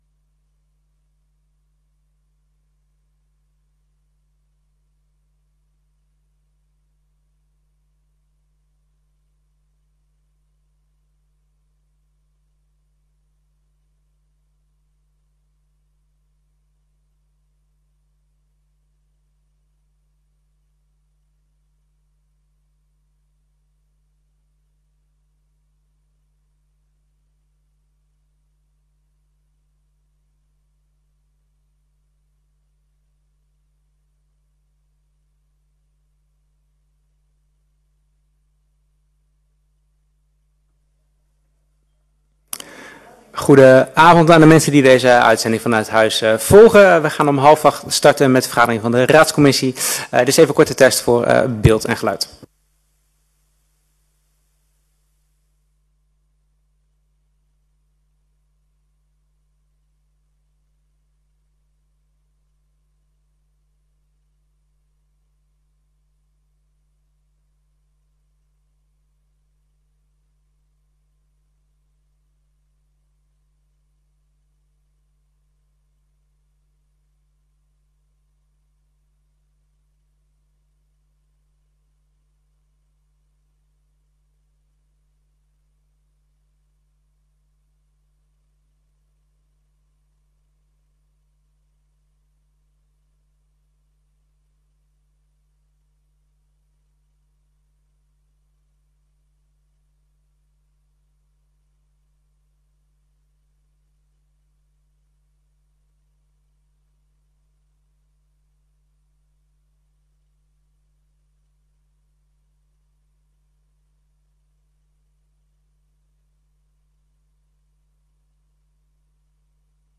Raadscommissie 13 mei 2024 19:30:00, Gemeente Dalfsen
Download de volledige audio van deze vergadering